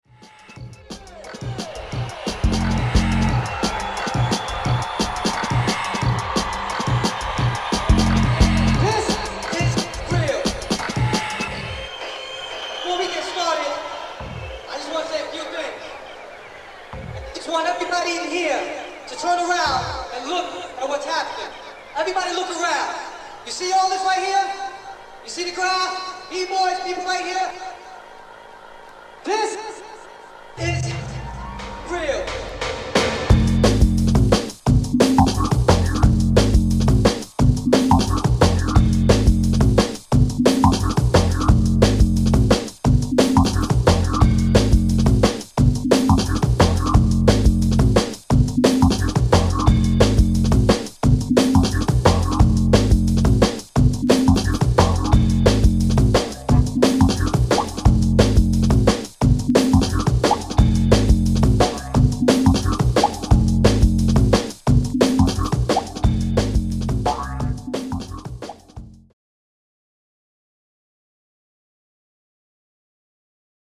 Jungle/Drum n Bass
Drum & Bass